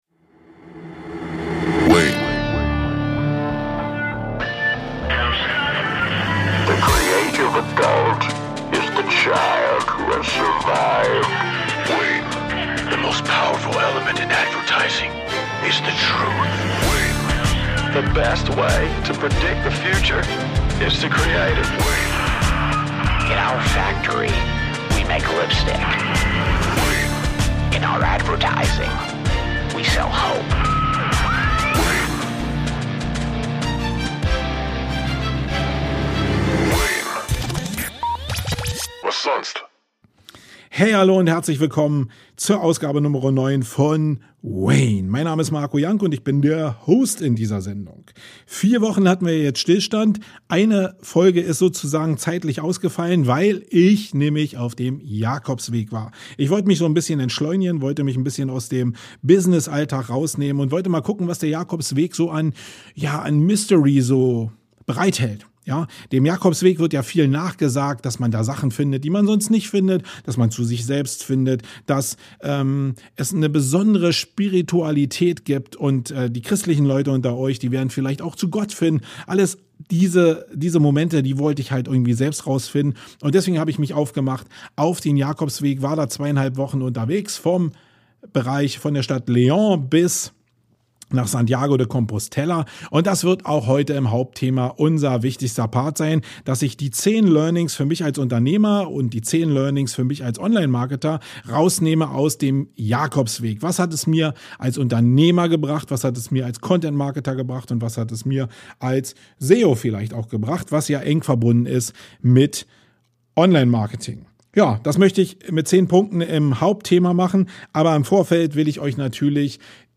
Gerade noch auf dem Jakobsweg und schon wieder vor dem Mikrofon im Podcast Studio.